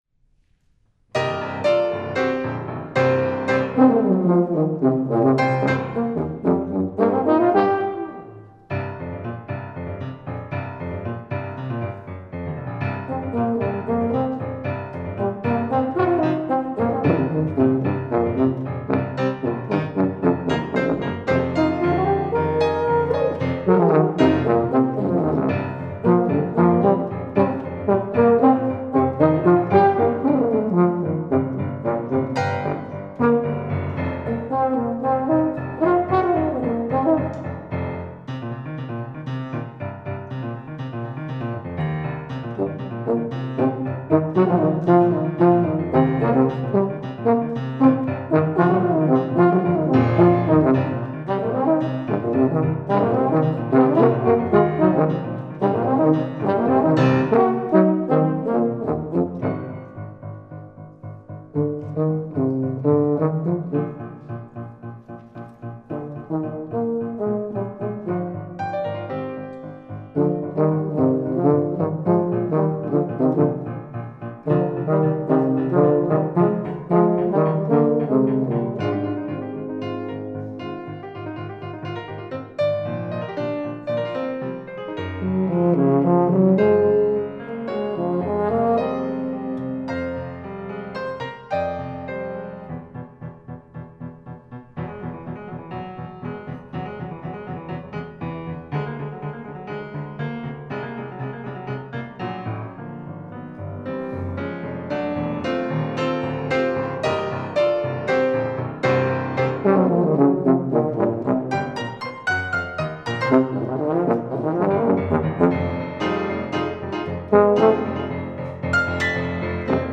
Faculty Recital 4-5-2012
tuba
piano